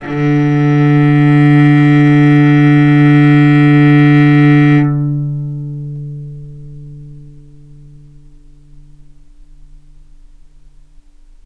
vc-D3-mf.AIF